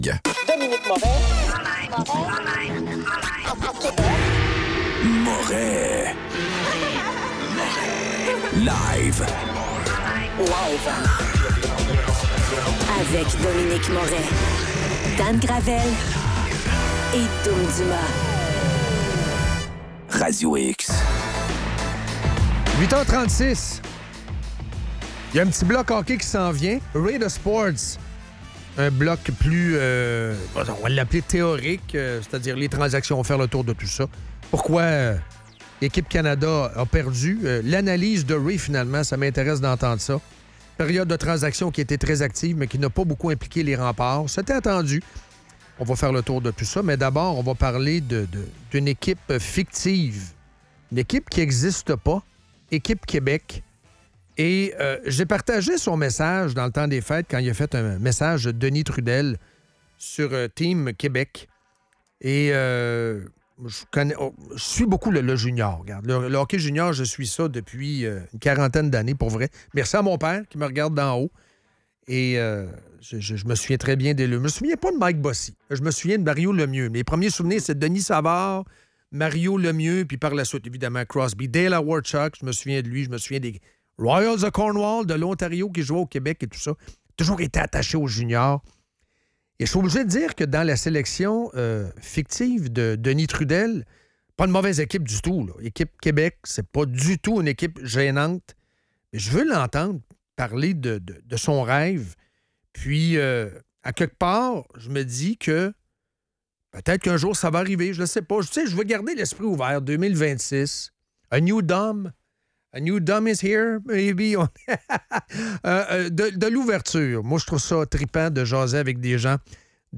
En entrevue, Denis Trudel